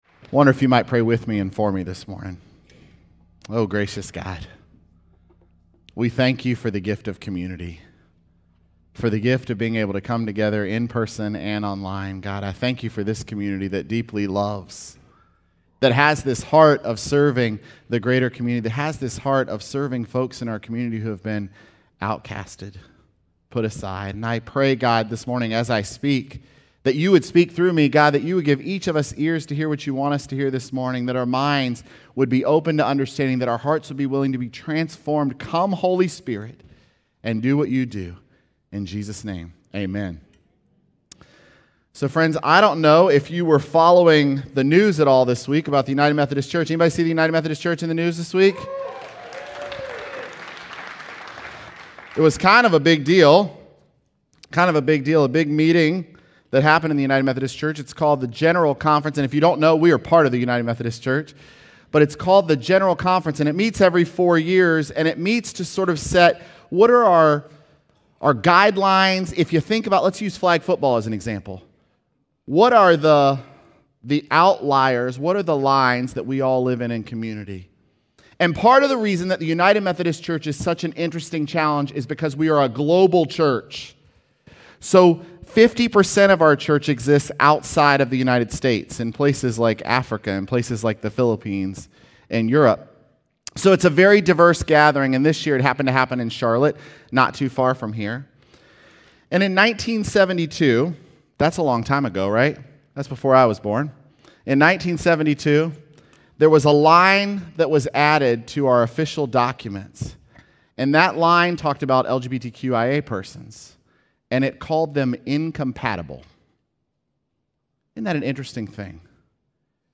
May5SermonPodcast.mp3